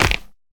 creaking_sway3.ogg